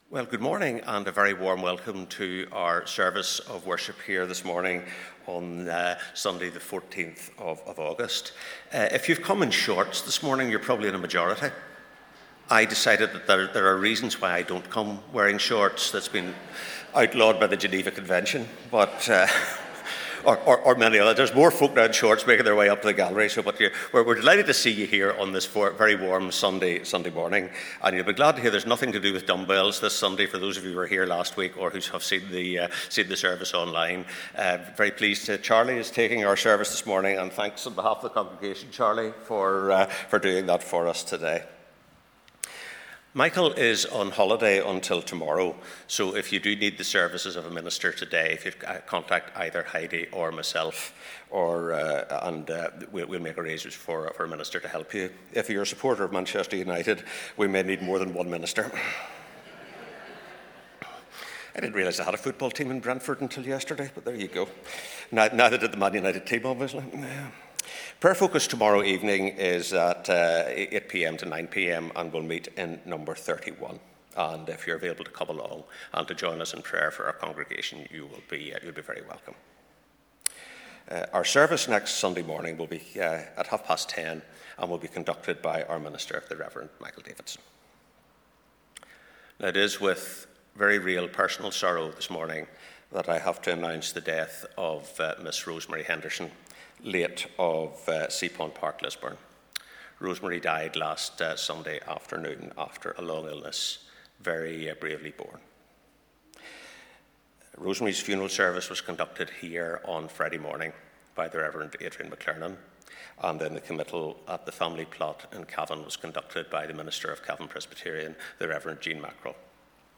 Sermon Points: A story not just about wine A story also about wine A story about faith
Morning Service